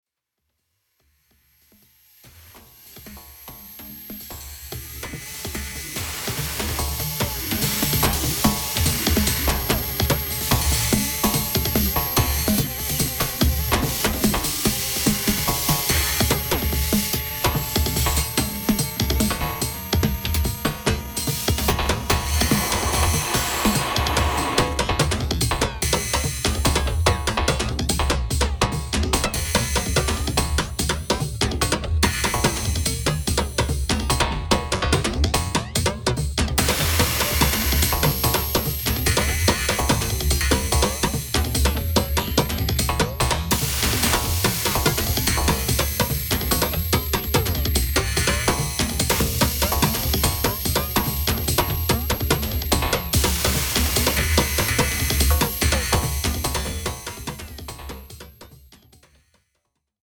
今回もガムラン的なパーカッションアレンジに血湧き肉躍る熱帯生まれの新型インダストリアルを展開。